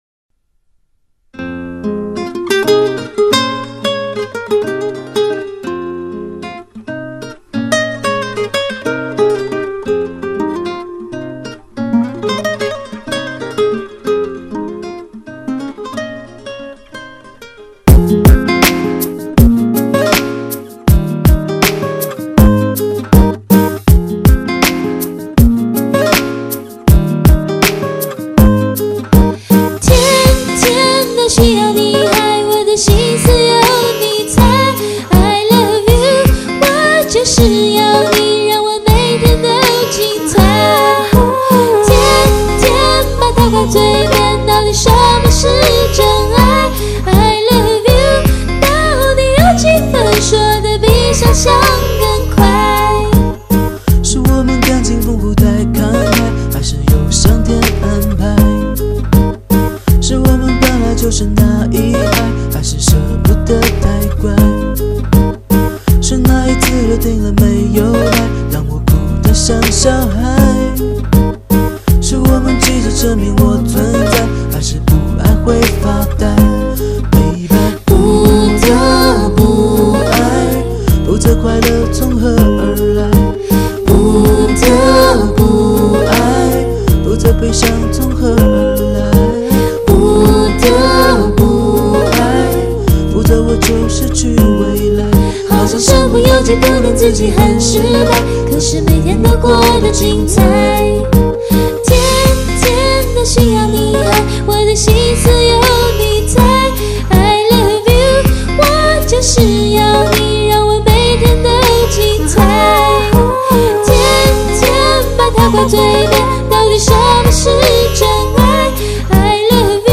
第一次全用假声唱歌